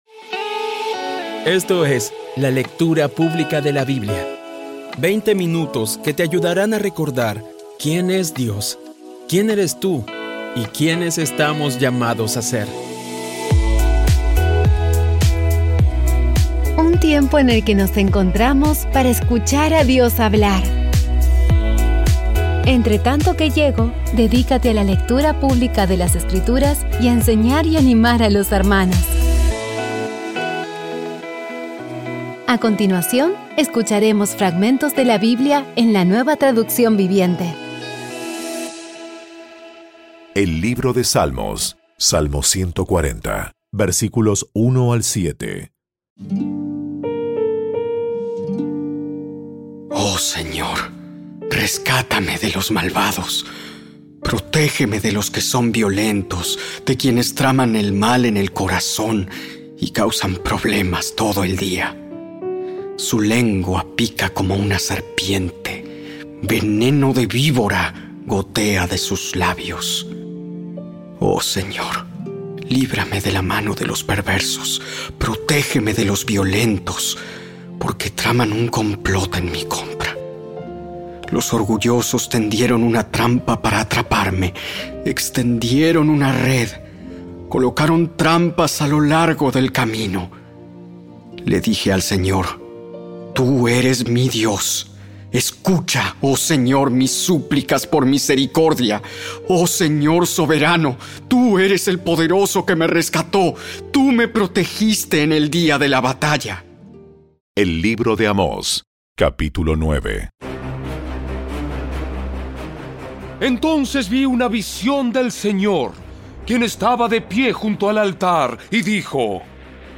Audio Biblia Dramatizada Episodio 347
Poco a poco y con las maravillosas voces actuadas de los protagonistas vas degustando las palabras de esa guía que Dios nos dio.